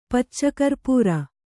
♪ pacca karpūra